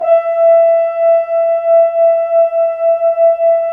Index of /90_sSampleCDs/Roland LCDP06 Brass Sections/BRS_F.Horns 2 mf/BRS_FHns Dry mf